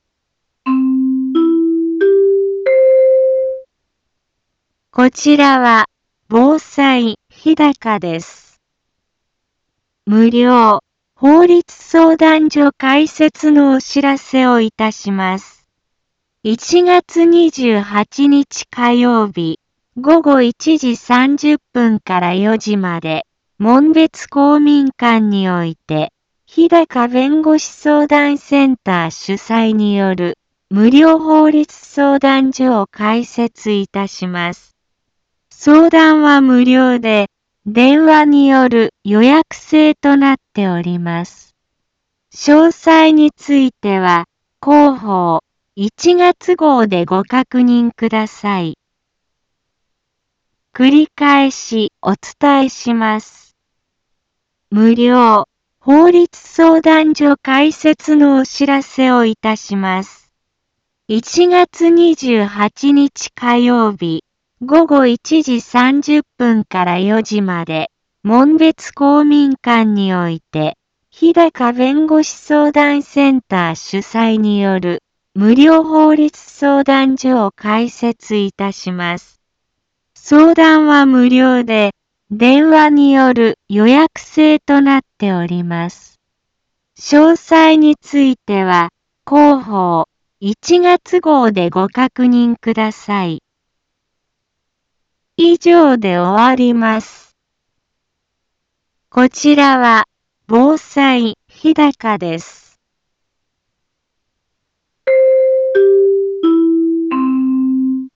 一般放送情報
Back Home 一般放送情報 音声放送 再生 一般放送情報 登録日時：2025-01-21 10:03:48 タイトル：無料法律相談会のお知らせ インフォメーション： こちらは、防災日高です。 無料法律相談所開設のお知らせをいたします。